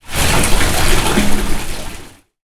liquid_drain.wav